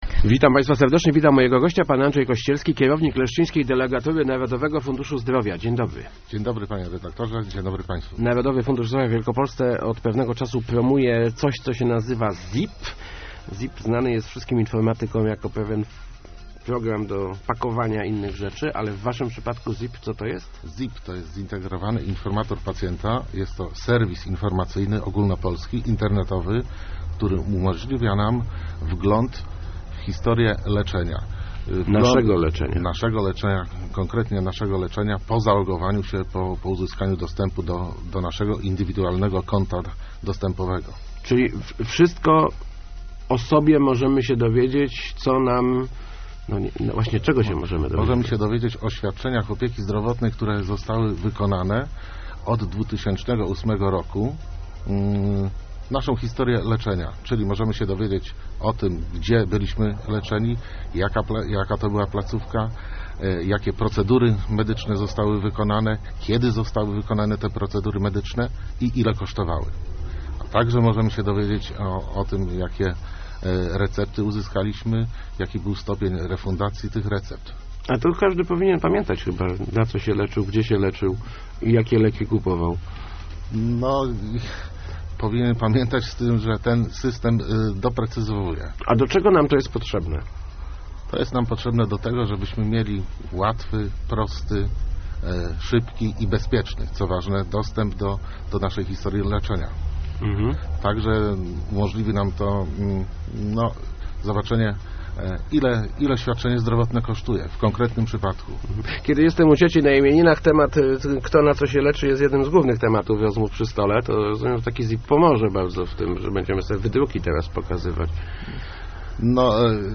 Start arrow Rozmowy Elki arrow Co to jest ZIP?